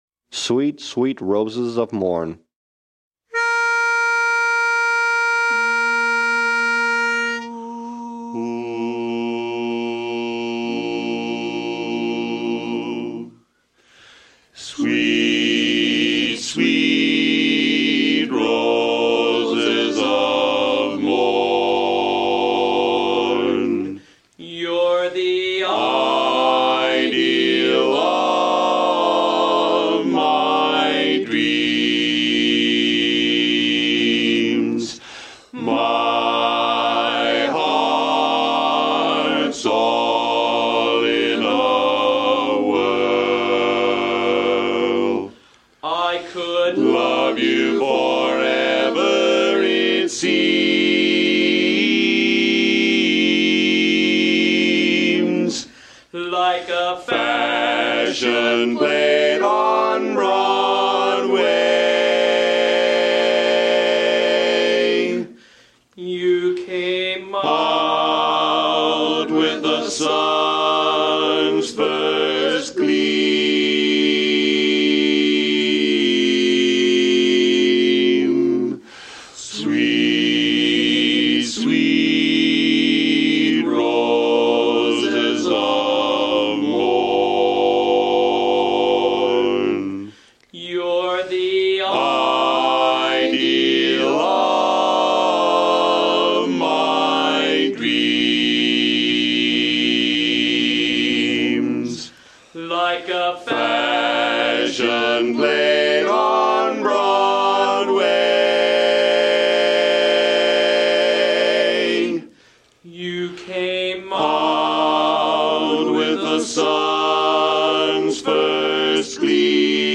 Von 19:15 bis 20:00 Uhr proben die NoNames, unser kleines, aber feines, Männerensemble, am gleichen Ort.
Sweet sweet Roses of Morn Bass